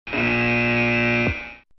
us_buzzer.mp3